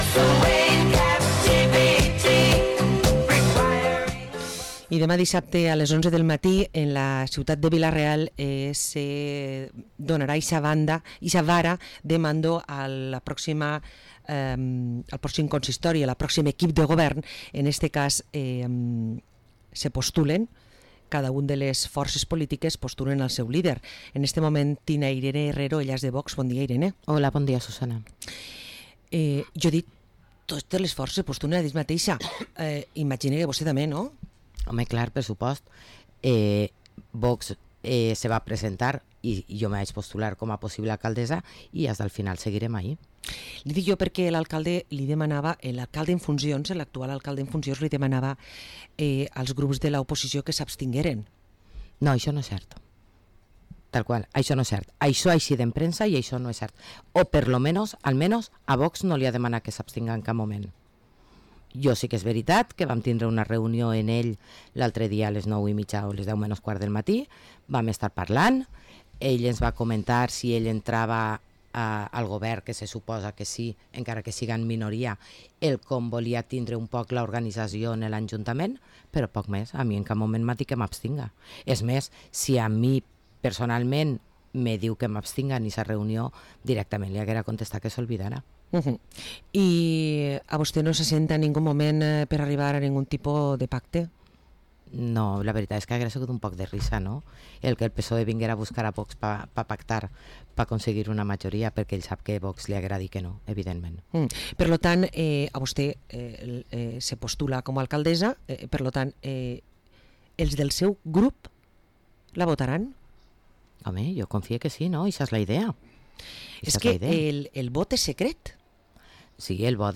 Entrevista a Irene Herrero, portaveu de VOX a l´Ajuntament de Vila-real